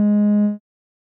Techmino/media/sample/bass/24.ogg at beff0c9d991e89c7ce3d02b5f99a879a052d4d3e